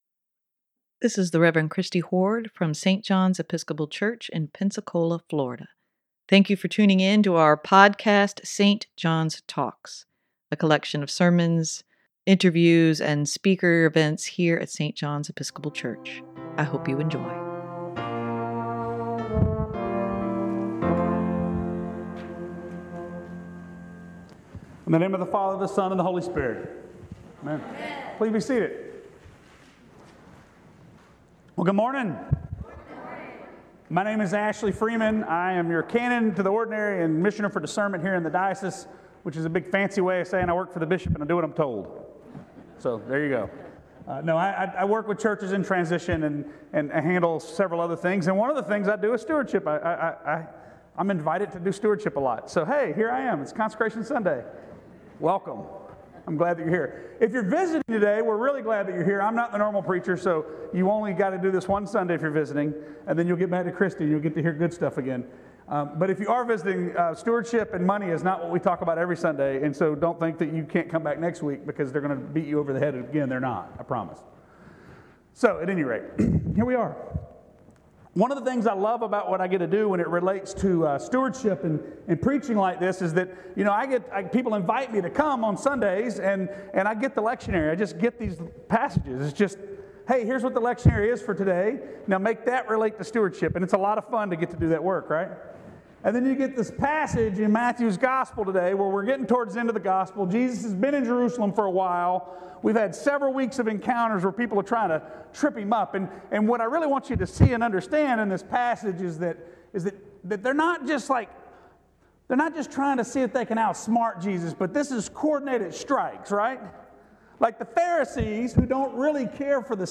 Sermon for Oct. 29, 2023: Called to love our neighbor as ourselves - St. John's Episcopal Church